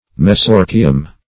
Search Result for " mesorchium" : The Collaborative International Dictionary of English v.0.48: Mesorchium \Me*sor"chi*um\, n. [NL., fr. Gr. me`sos middle + ? a testicle.]
mesorchium.mp3